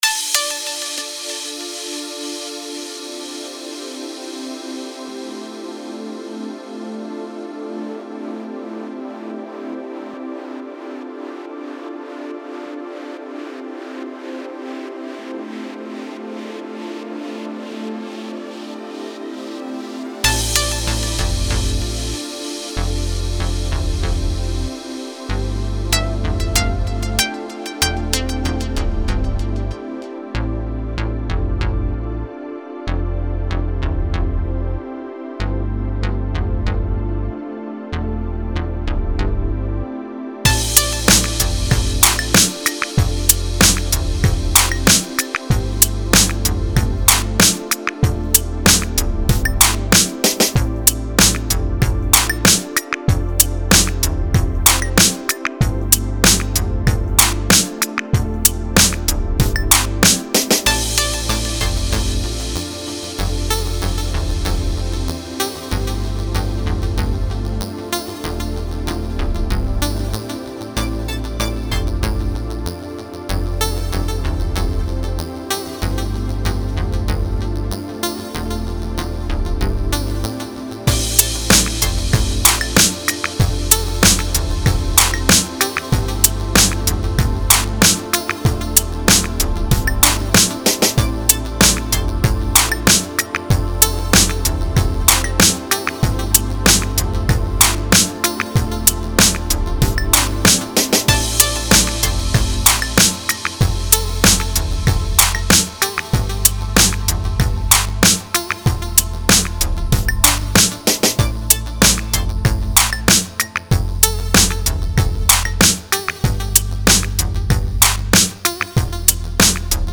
electronica